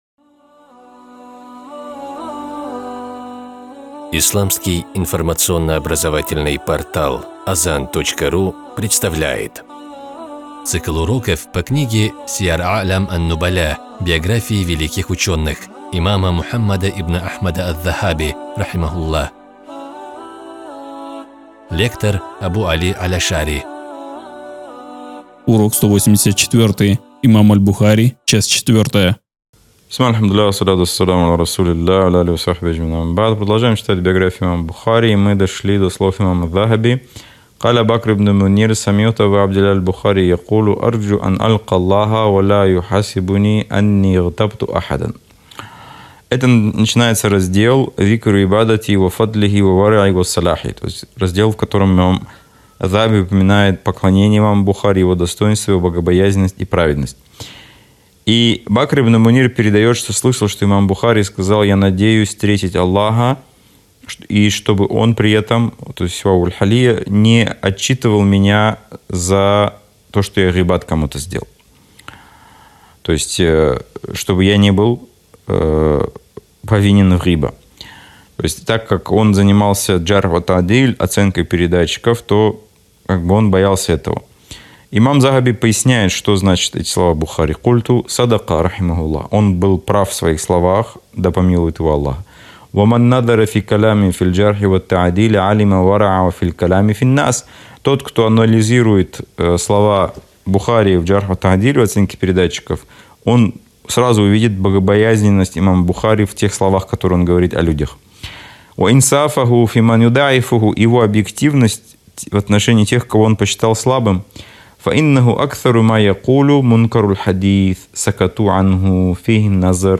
Цикл уроков по книге великого имама Аз-Захаби «Сияр а’лям ан-нубаля». Биографии исламских ученых для мусульман — не просто история, но и пример для подражания верующих, средство для улучшения их нрава.